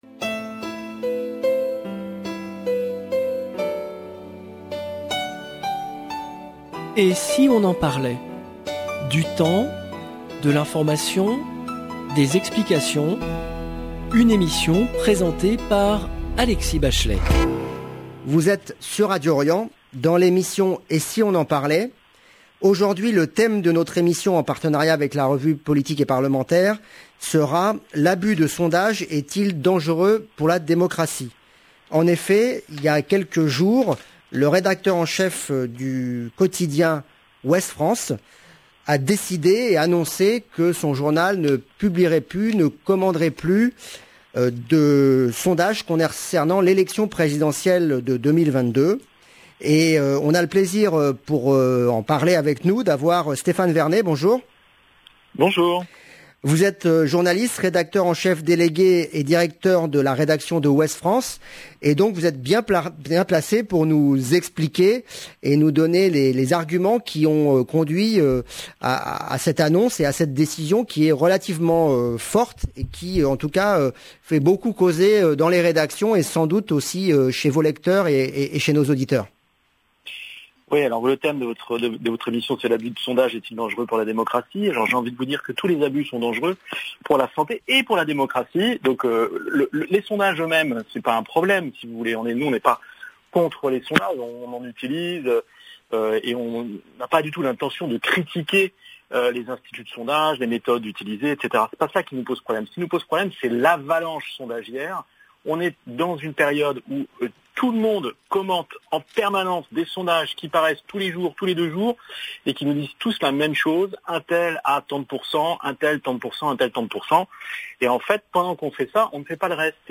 Les invités d'Alexis Bachelay